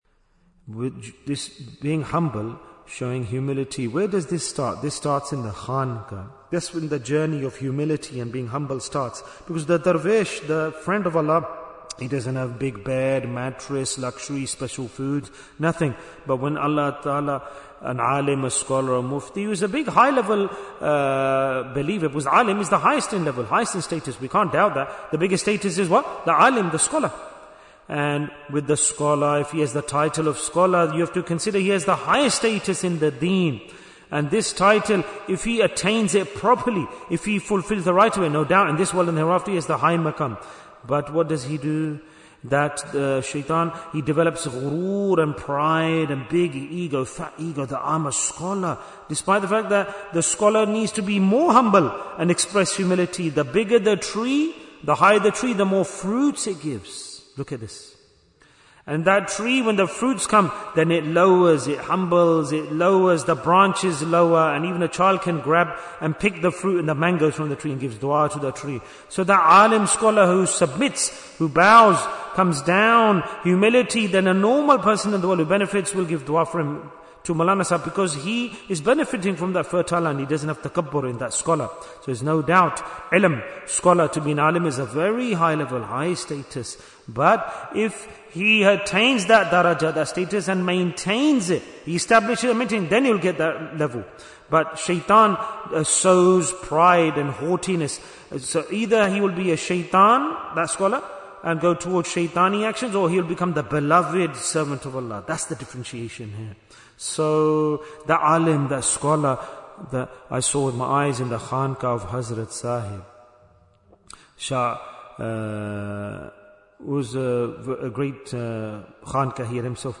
Why is Tazkiyyah Important? - Part 11 Bayan, 35 minutes23rd January, 2026